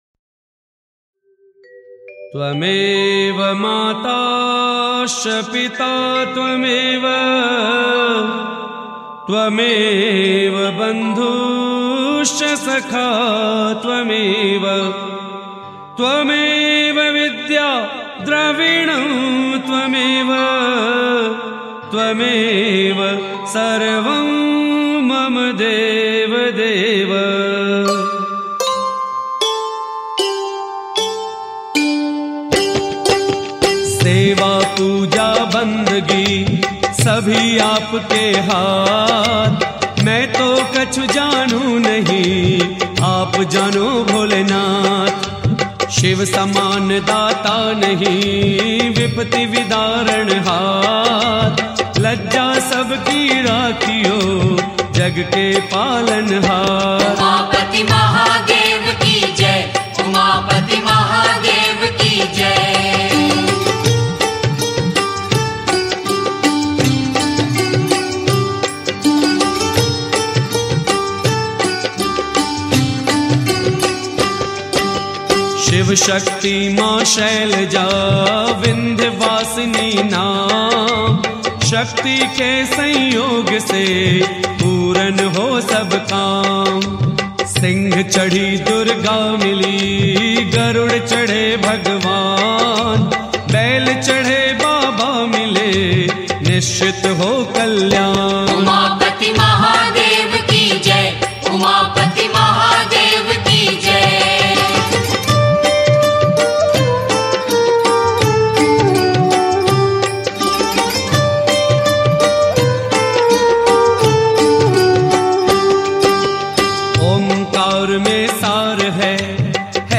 Devotional Songs > Shiv (Bholenath) Bhajans